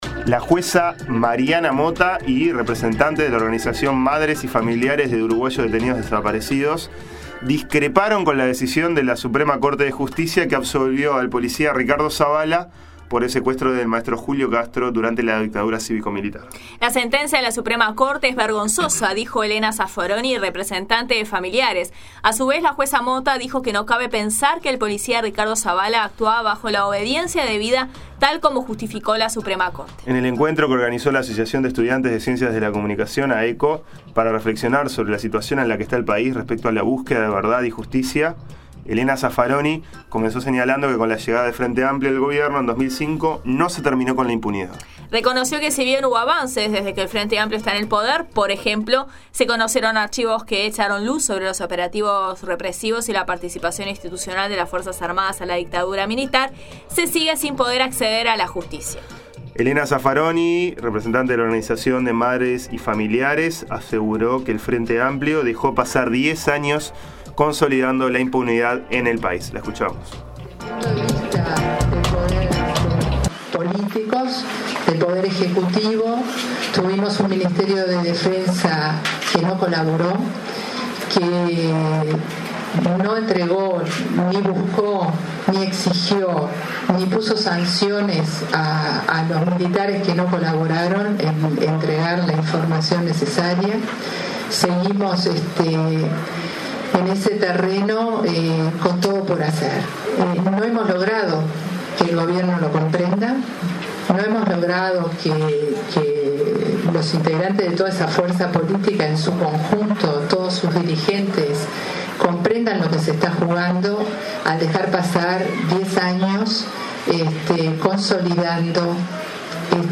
El martes 12 de mayo en el Instituto de Comunicación de la Facultad de Información y Comunicación se realizó un Panel que invitó a reflexionar sobre los avances en materia de Derechos Humanos acontecidos en Uruguay desde la primera Marcha del Silencio en 1996.